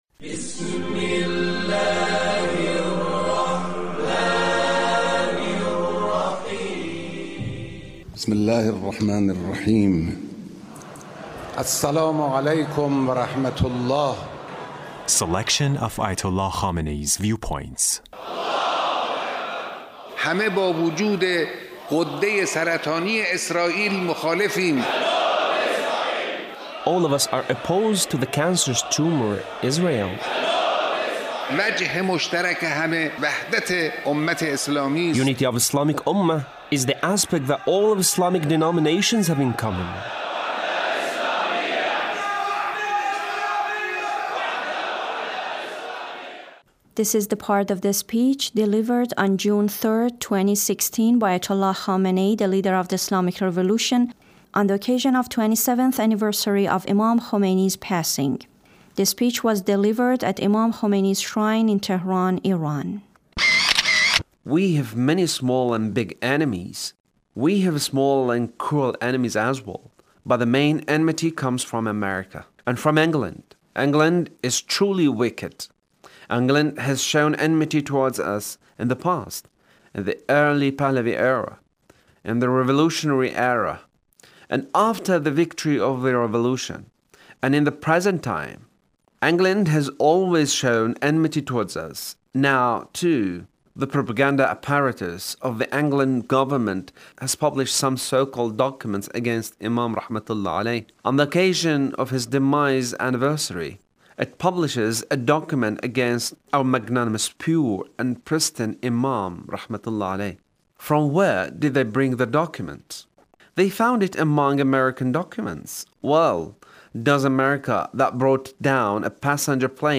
Leader's speech (1350)